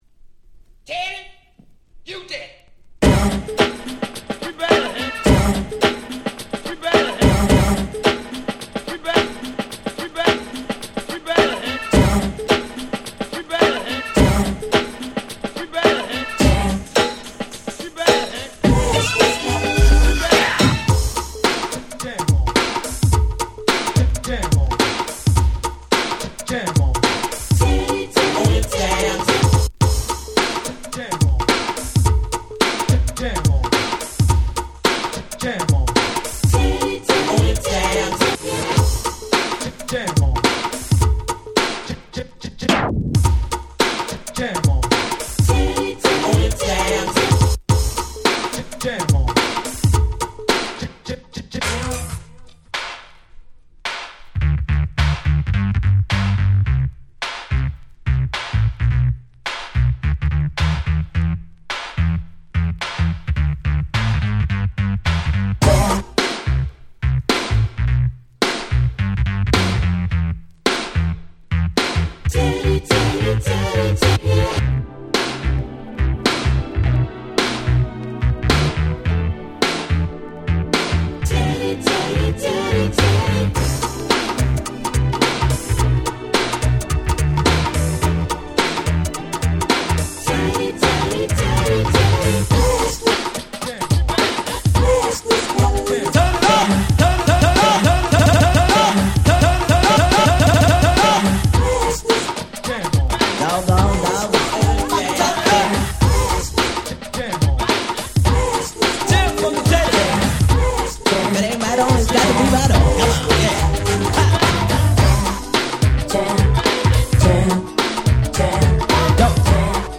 言わずと知れた最高のNew Jack Swing !!